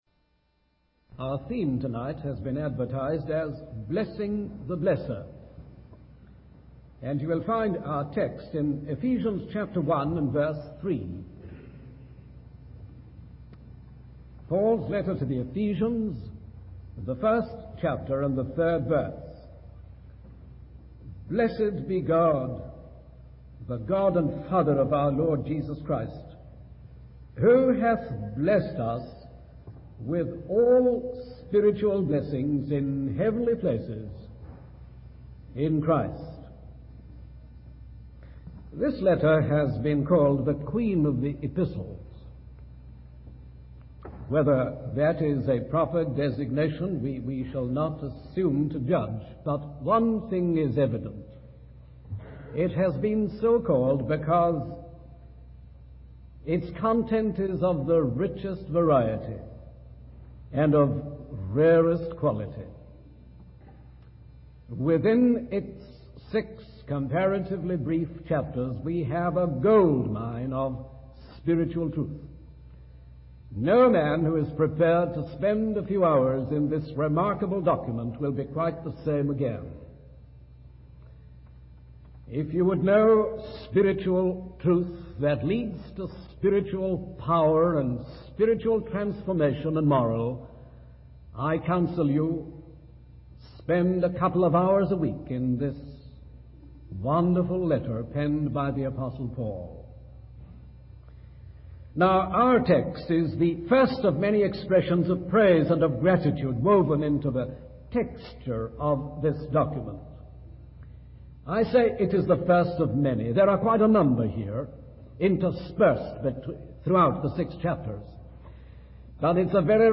In this sermon, the speaker emphasizes the importance of staying connected to God's power and grace in order to live a fulfilling spiritual life. He uses the analogy of trolley arms connecting to electric pylons to illustrate this concept.